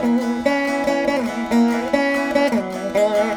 142  VEENA.wav